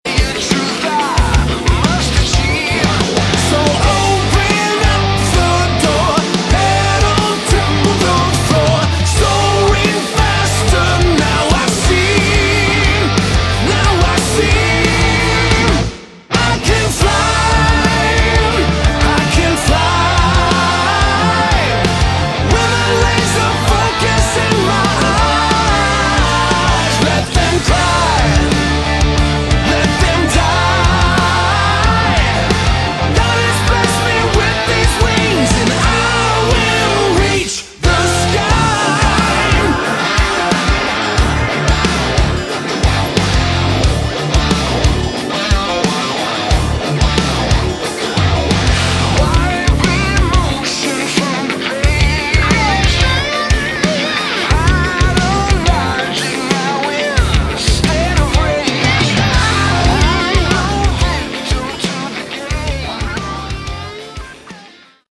Category: Hard Rock
guitars
lead vocals
drums
bass
keyboards
backing vocals